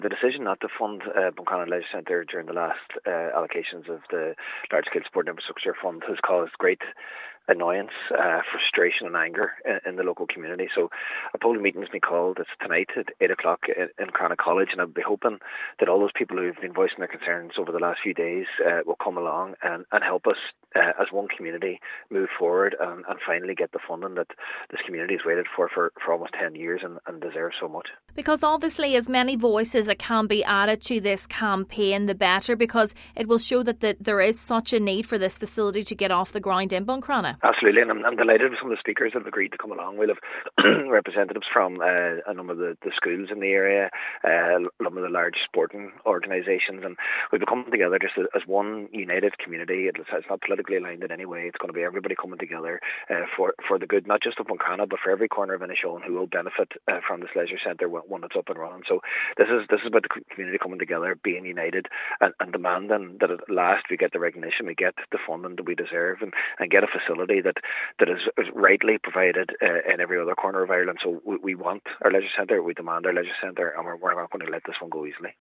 Cathaoirleach of the Inishowen Municipal District, Councillor Jack Murray is urging people to come out and send a strong message to those with the power to sanction the funding: